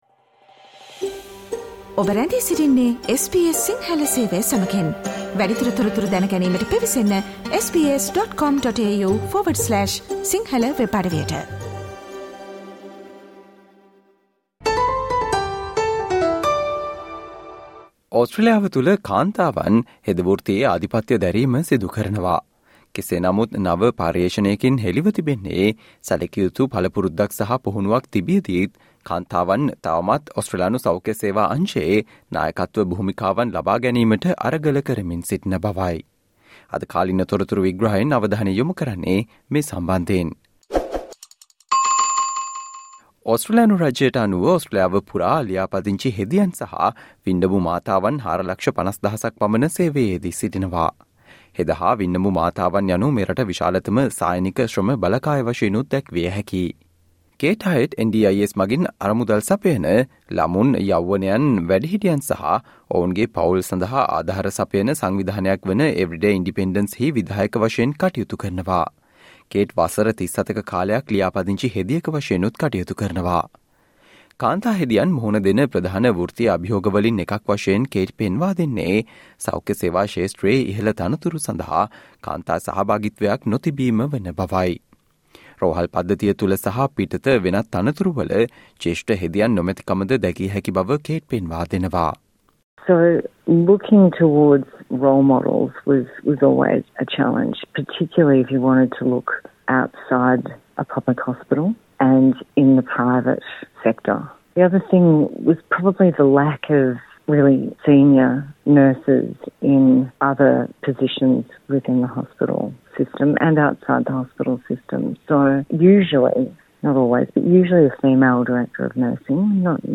However new research has revealed women are still struggling to land leadership roles in the healthcare sector, despite significant experience and training. Listen to this SBS Sinhala explainer for more information.